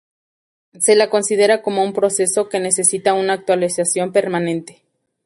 per‧ma‧nen‧te
/peɾmaˈnente/